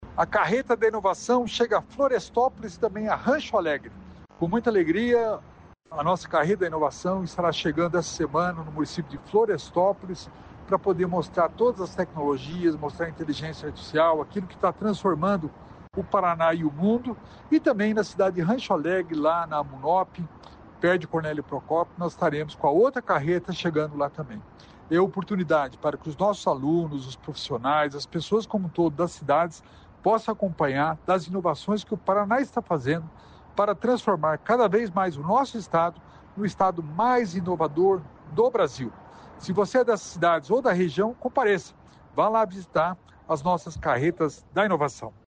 Sonora do secretário da Inovação e Inteligência Artificial, Alex Canziani, sobre a Carreta da Inovação